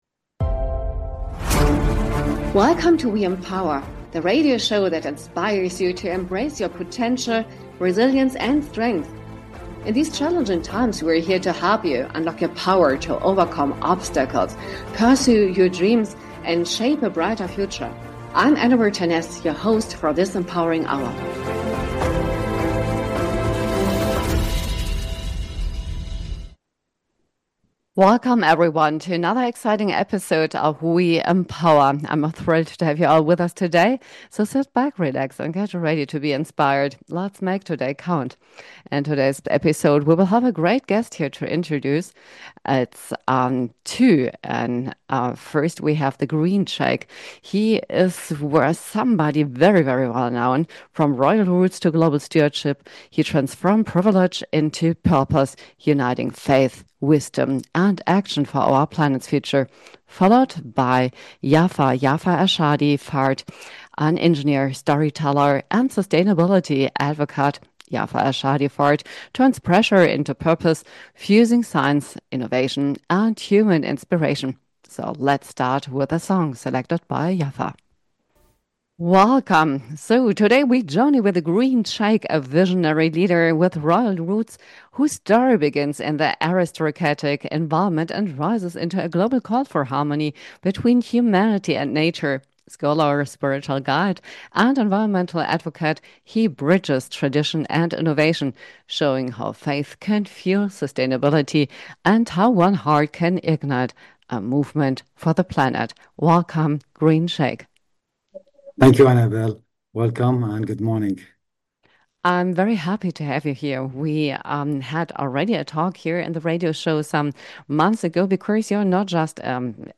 Welcome to "WE EMPOWER" – a radio show inspiring women to unleash their strengths and thrive in various life aspects.
Featuring interviews with impressive female personalities across professions and discussions on women-led businesses, the show celebrates pioneers, especially female pioneers in history, science, art, and culture. It also explores self-development, mental health, and wellbeing, showcasing the most inspiriting books on these topics.